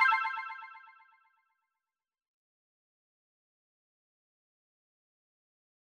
confirm_style_4_echo_004.wav